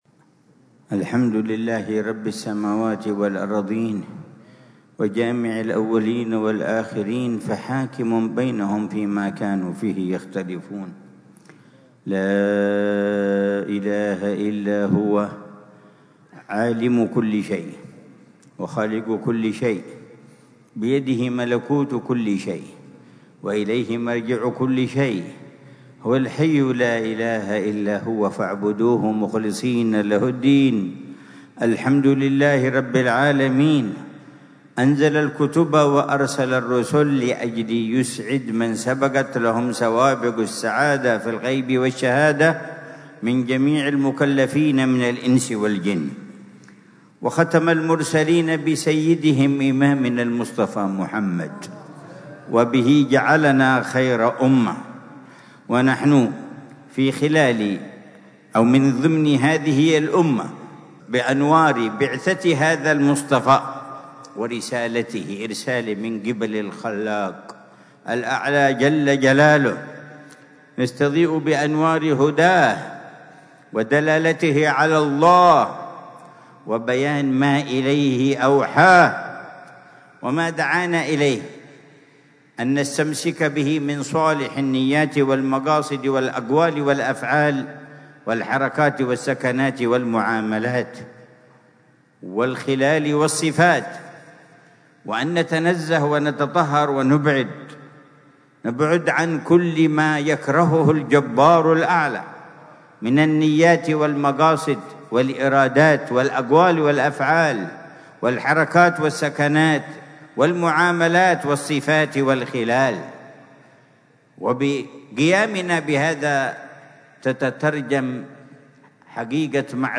محاضرة العلامة الحبيب عمر بن محمد بن حفيظ ضمن سلسلة إرشادات السلوك، ليلة الجمعة 4 ذو القعدة 1446هـ في دار المصطفى بتريم، بعنوان: